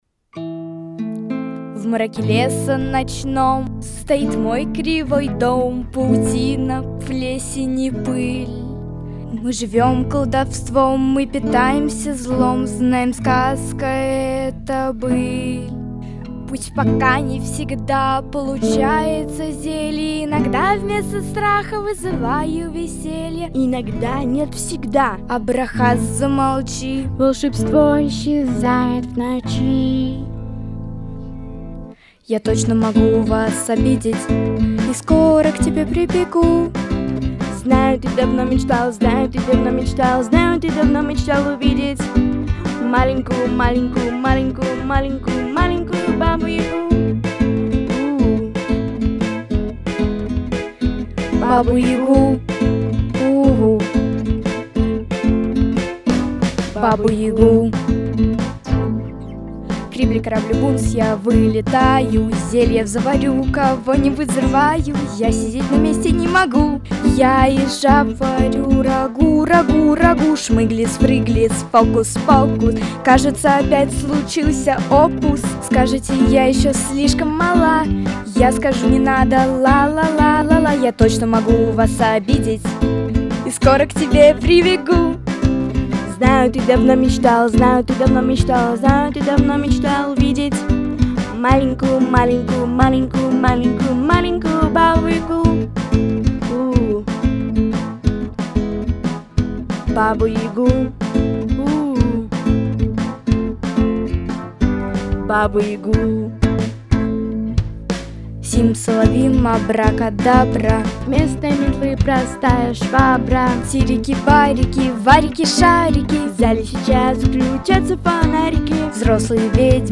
Записано в студии Easy Rider в ноябре–декабре 2025 года